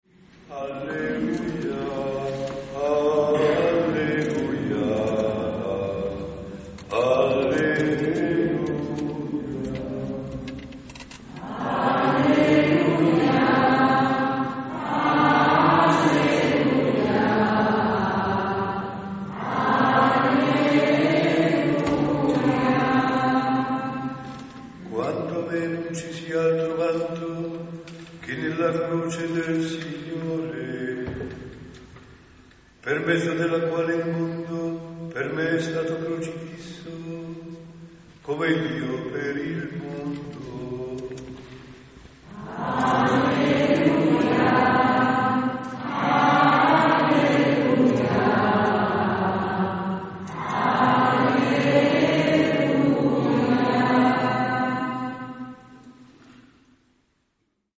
Rualis d Cividale (UD), 15 Settembre 2024
Santa Messa nella XXIV Domenica del T. O.
...inizio della celebrazione...